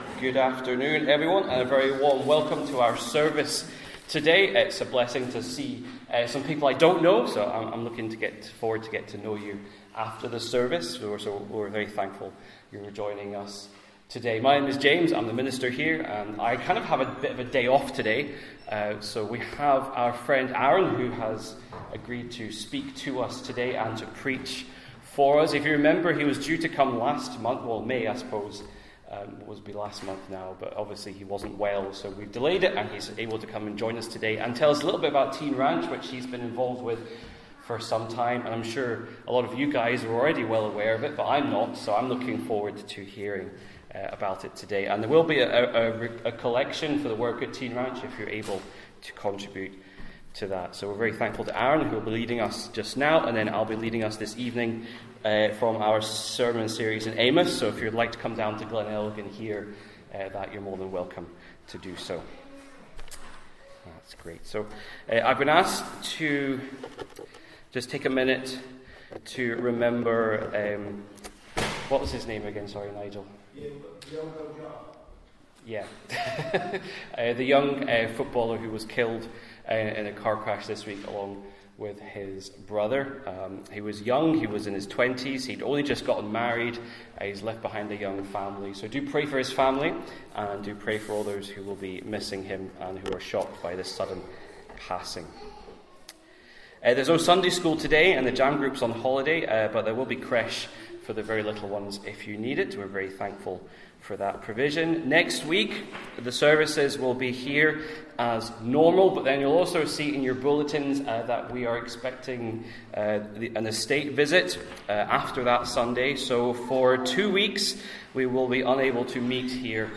Passage: Exodus 20:1-21 Service Type: Inverinate AM Download Files Bulletin « The Church